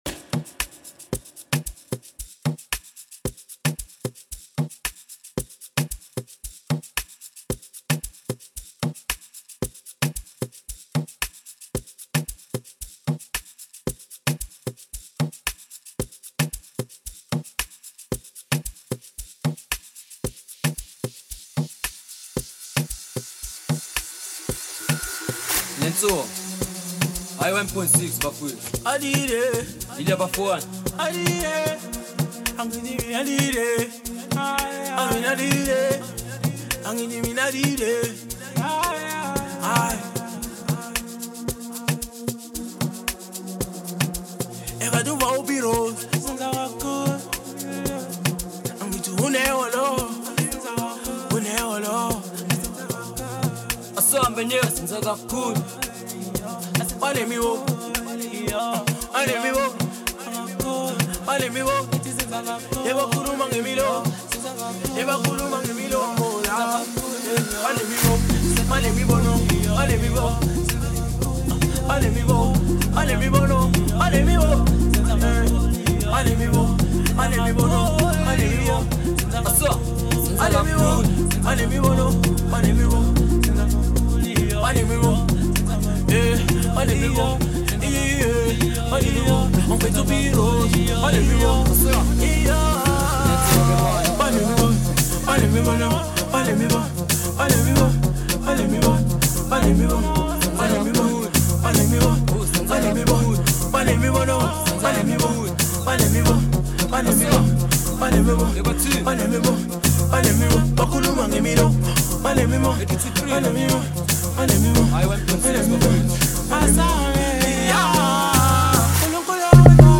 06:15 Genre : Amapiano Size